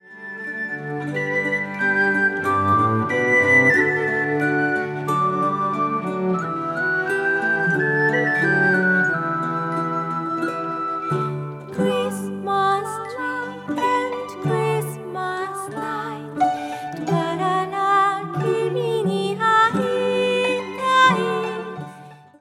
ヴィオラ・ダ・ガンバ